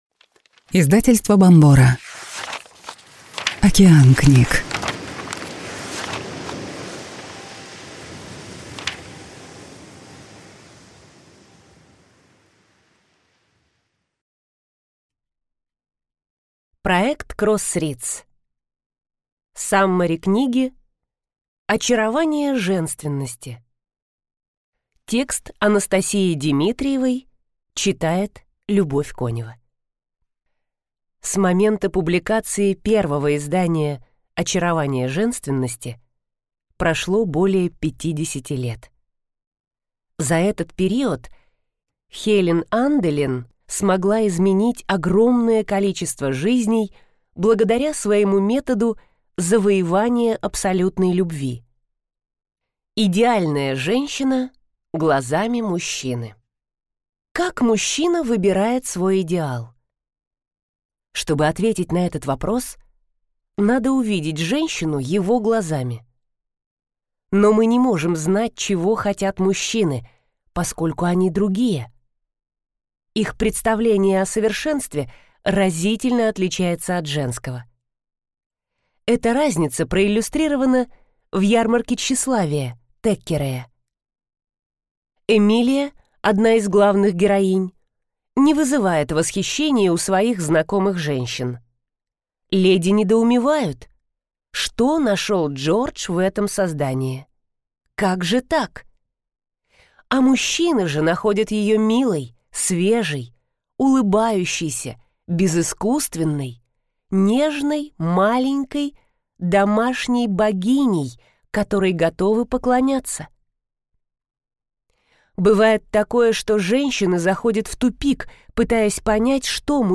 Аудиокнига Саммари книги «Очарование женственности» | Библиотека аудиокниг